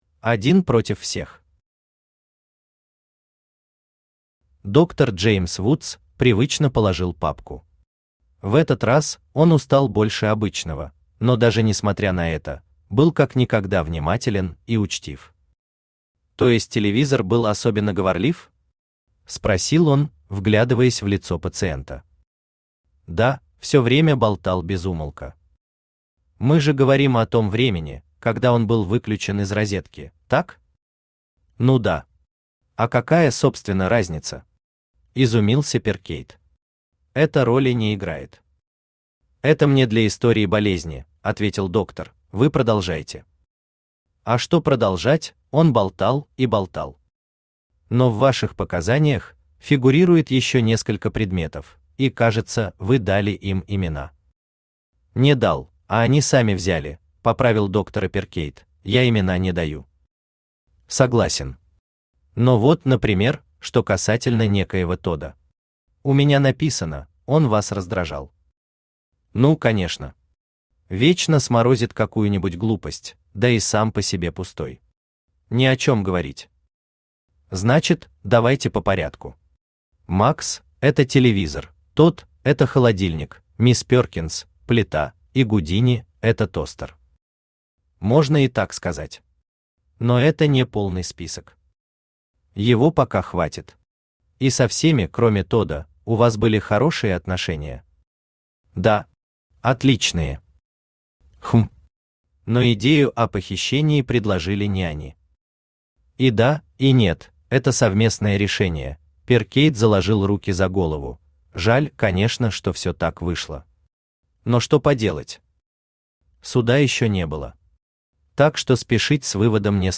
Аудиокнига Больница Арчибальд. Записки психиатра | Библиотека аудиокниг
Aудиокнига Больница Арчибальд. Записки психиатра Автор Даниил Заврин Читает аудиокнигу Авточтец ЛитРес.